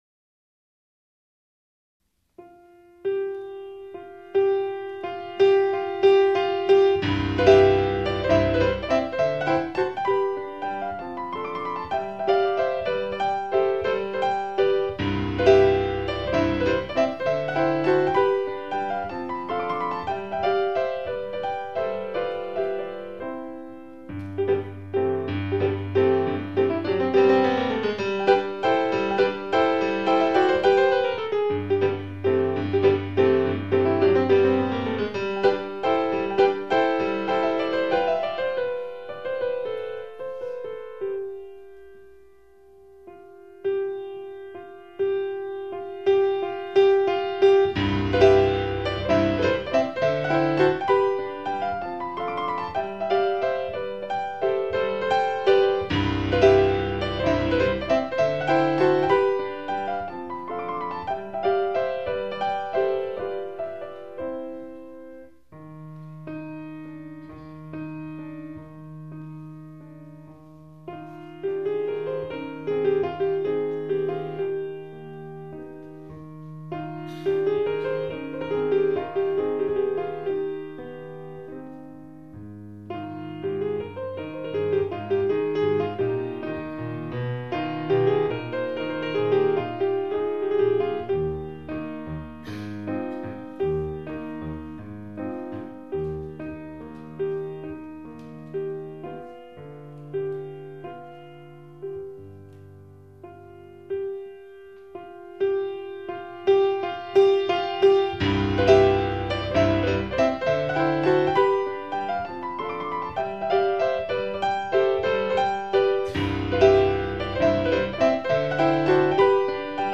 Mazurka (C Major). 2.7 Mbyte.
Live Recording  - Rome 1994